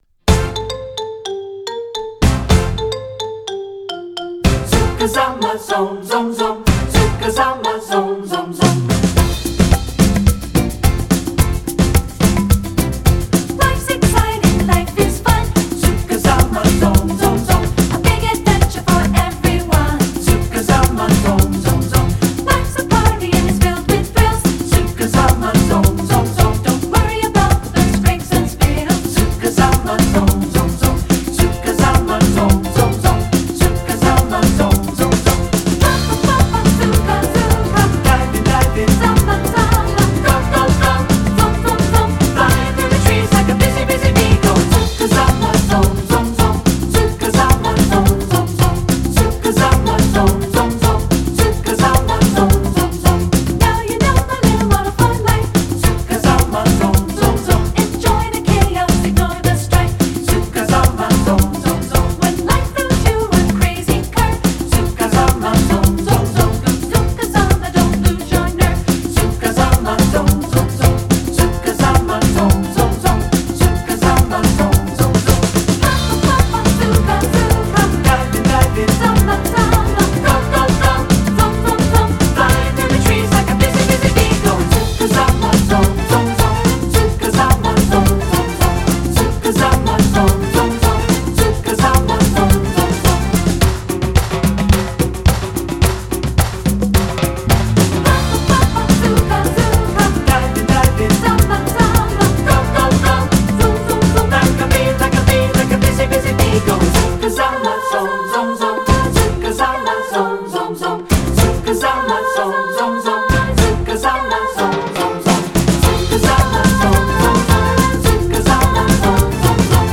Voicing: 3-Part Mixed and Piano